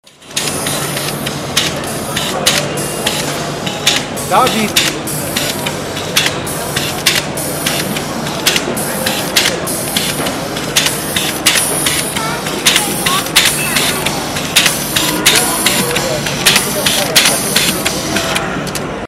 hund.mp3